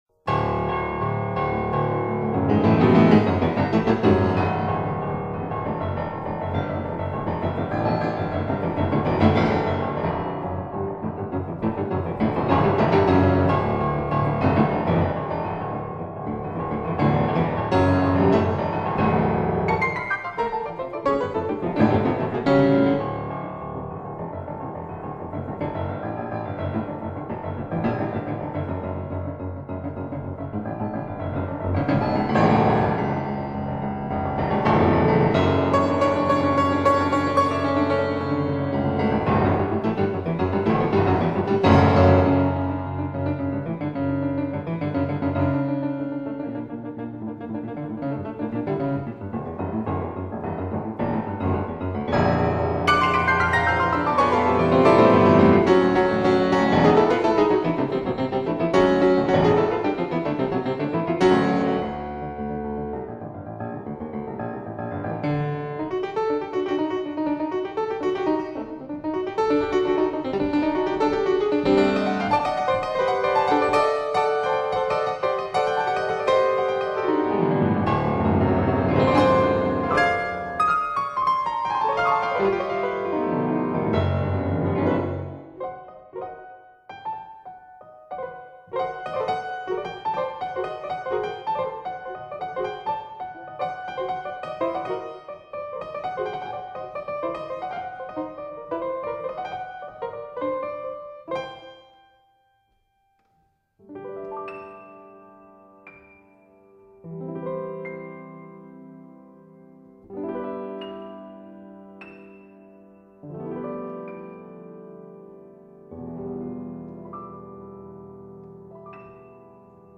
Here are a few of my own compositions for piano.
Dance.wma